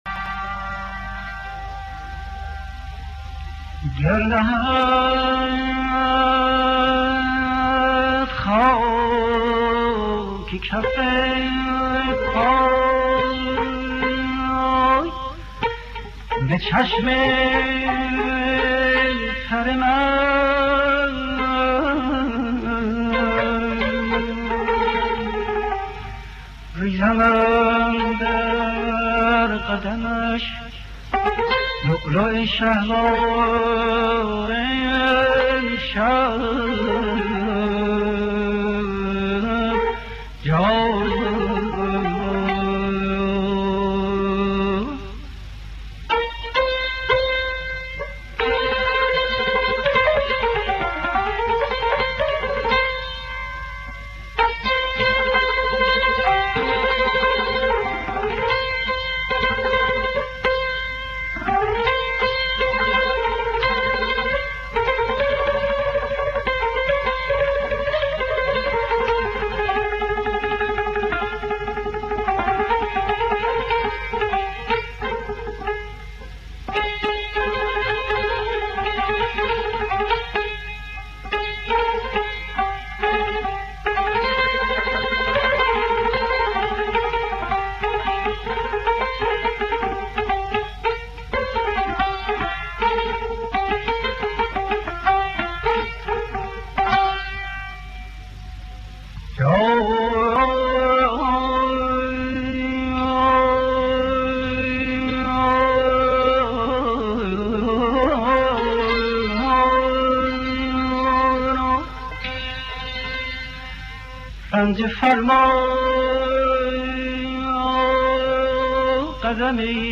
دانلود گلهای رنگارنگ ۵۶۸ با صدای حسین قوامی، الهه در دستگاه دشتی.
خوانندگان: حسین قوامی الهه نوازندگان: رضا ورزنده